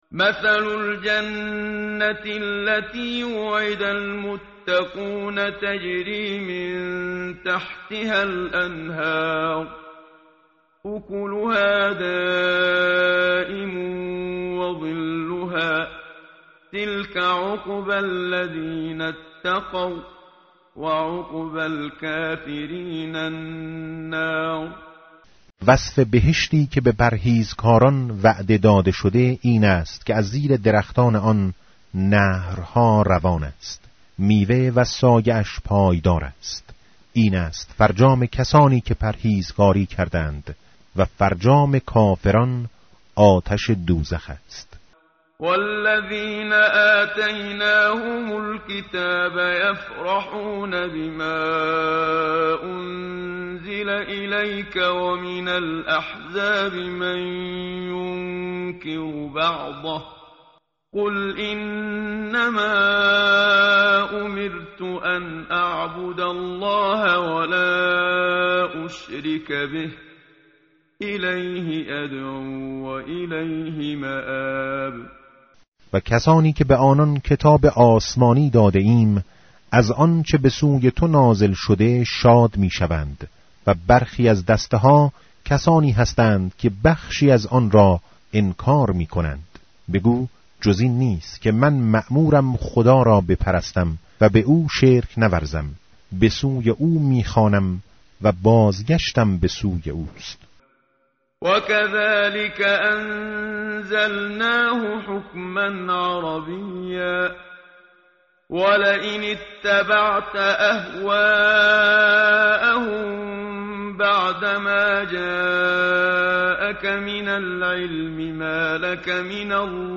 متن قرآن همراه باتلاوت قرآن و ترجمه
tartil_menshavi va tarjome_Page_254.mp3